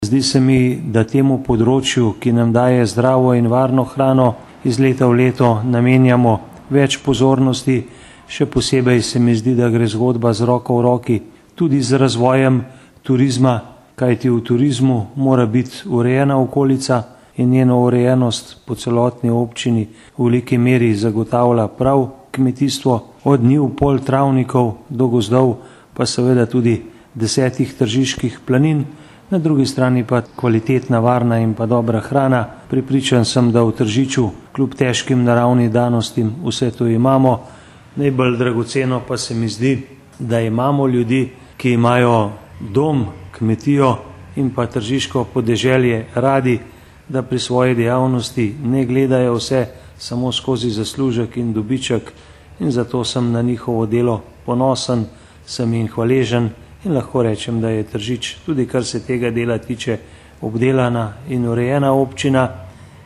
izjava_zupanobcinetrzicmag.borutsajovicokmetijskemrazpisu.mp3 (1,5MB)